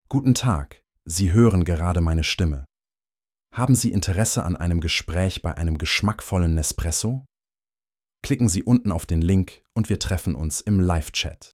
Stimmprofil
Auf Basis meines Persönlichkeitsprofils klinge ich warm, kultiviert und klangvoll – und immer gelassen.
Nespresso-Stimmprofil-DE-ma╠ennlich.mp3